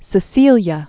(sĭ-sēlyə), Saint Third century AD.